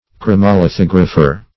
Search Result for " chromolithographer" : The Collaborative International Dictionary of English v.0.48: Chromolithographer \Chro`mo*li*thog"ra*pher\, n. One who is engaged in chromolithography.